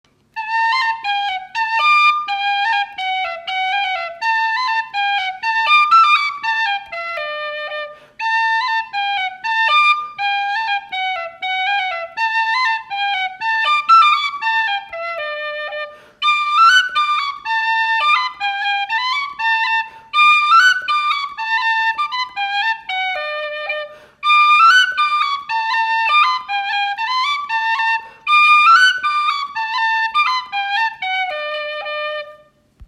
Bhí na píosaí casta go han-mhall ar an dtaifead sin ach tá siad anseo arís- casta ag gnáthluas agus casta níos fearr!!